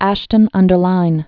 (ăshtən-ŭn-dər-līn)